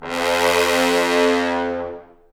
Index of /90_sSampleCDs/Roland LCDP06 Brass Sections/BRS_Bs.Trombones/BRS_Bs.Bone Sect